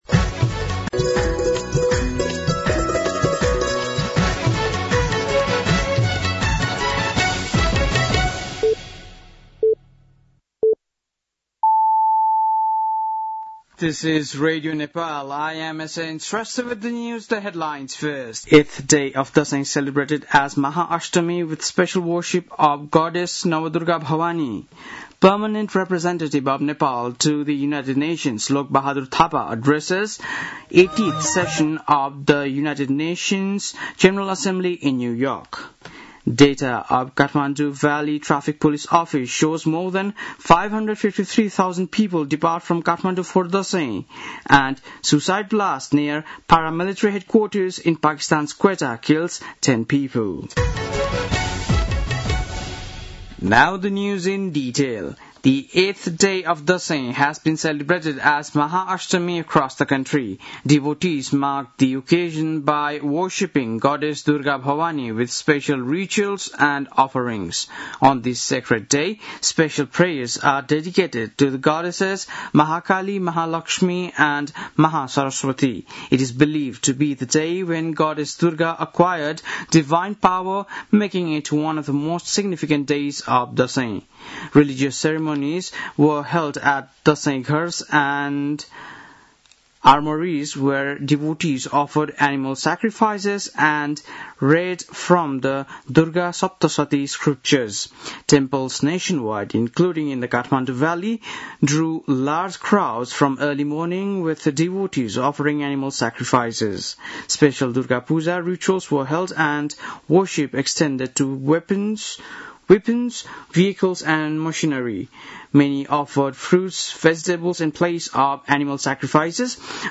बेलुकी ८ बजेको अङ्ग्रेजी समाचार : १४ असोज , २०८२